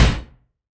SNARE 108.wav